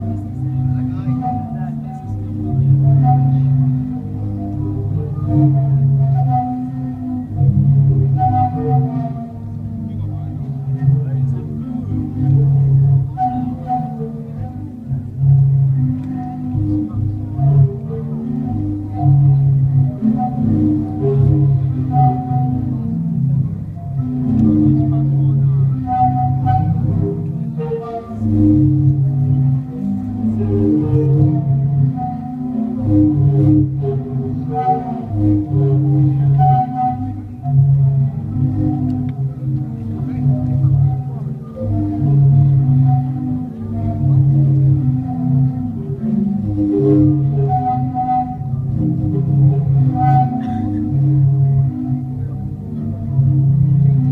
Croatia's sea organ
Field recording
sea organ at Zadar, Croatia, playing a tune through the motion of the waves.